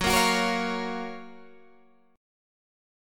F#M7 chord